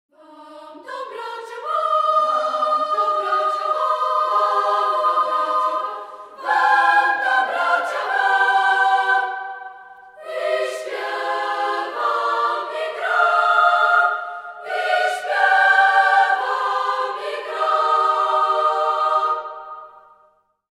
wykonuje chór dziewczêcy